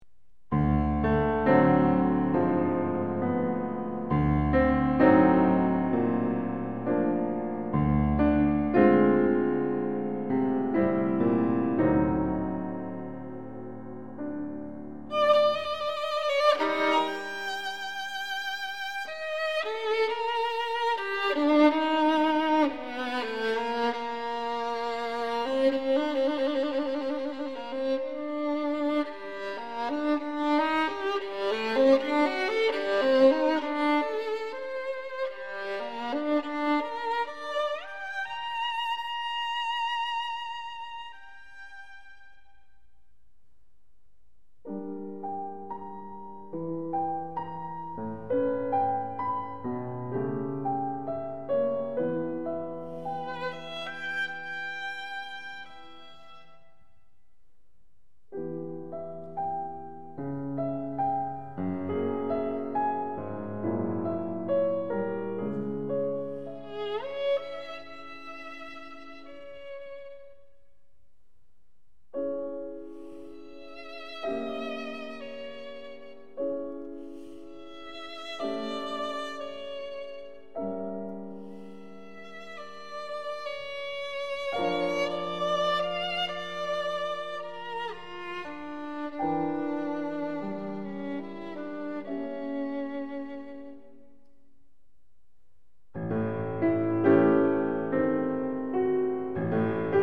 ★ 著名工程師採單點錄音，高傳真效果完美呈現！
★ 令人顫抖著迷的小提琴美音之最，發燒必備珍品！